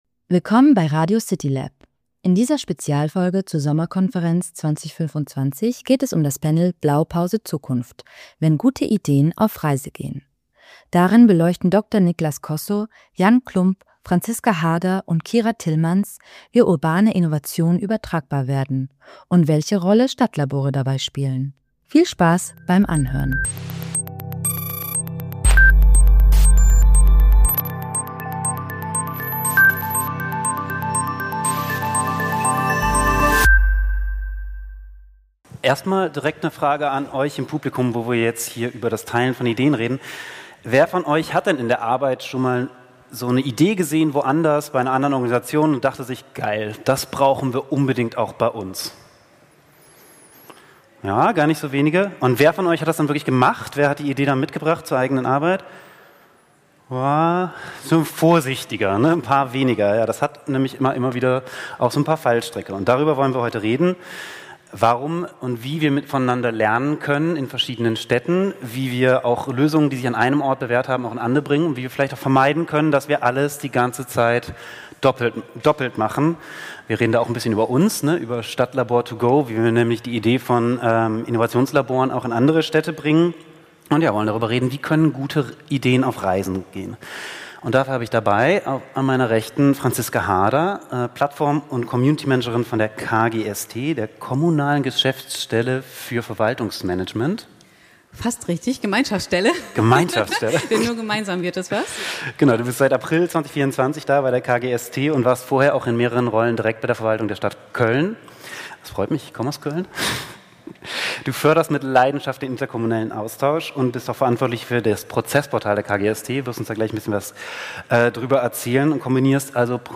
CityLAB Sommerkonferenz 2025: Das Podcast-Special Obwohl in vielen Städten bereits bewährte Lösungen und gute Ideen existieren, werden diese oft nicht von anderen Verwaltungen übernommen.